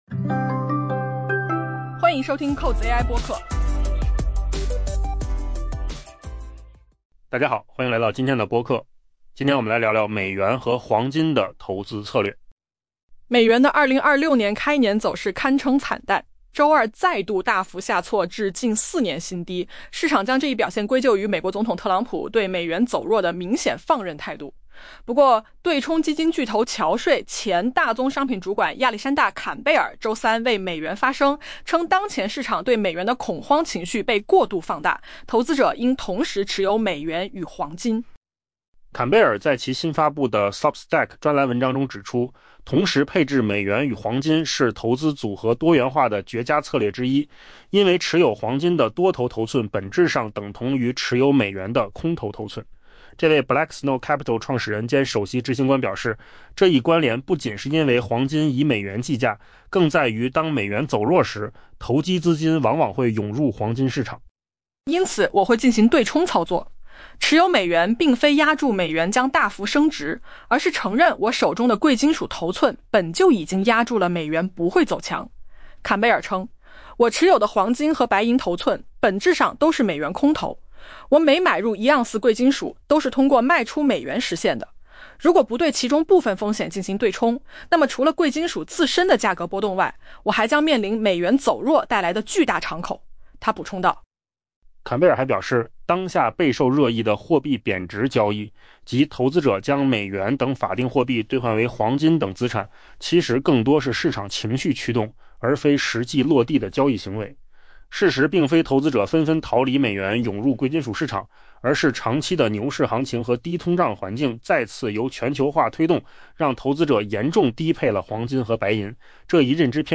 AI 播客：换个方式听新闻 下载 mp3 音频由扣子空间生成 美元的 2026 年开年走势堪称惨淡，周二再度大幅下挫至近四年新低，市场将这一表现归咎于美国总统特朗普对美元走弱的明显放任态度。